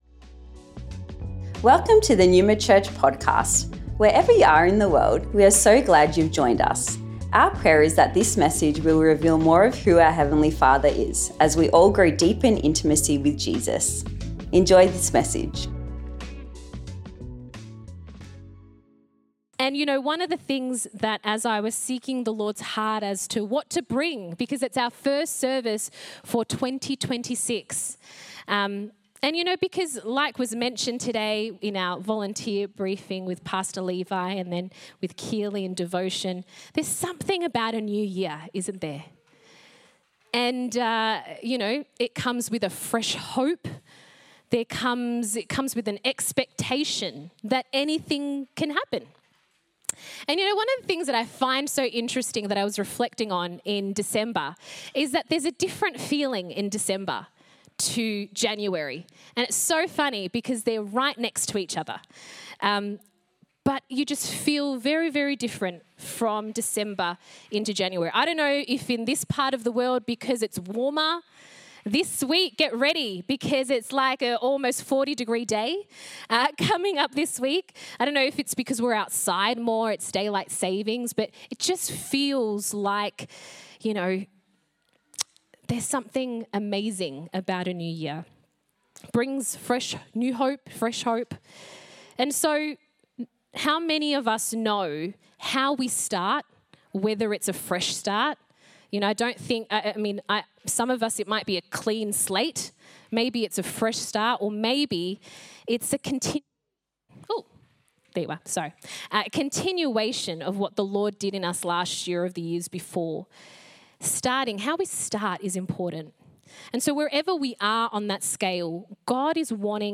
Recorded at Melbourne Neuma West